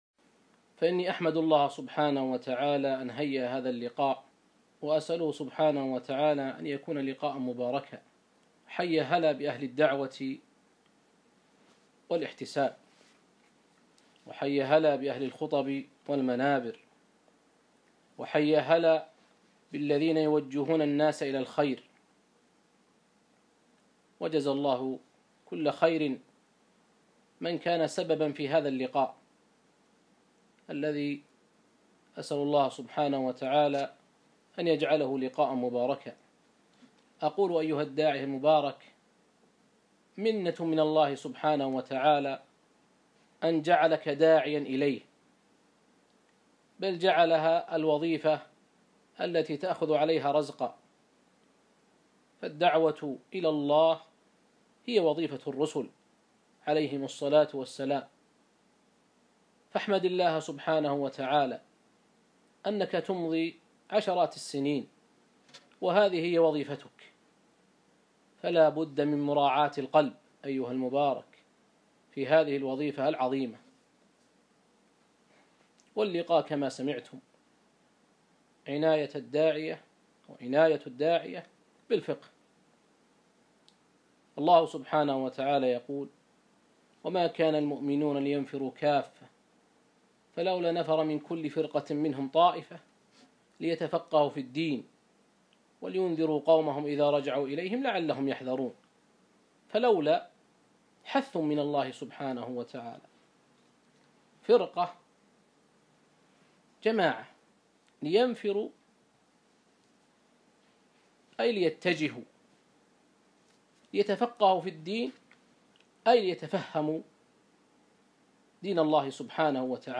محاضرة - عناية الداعية بالفقه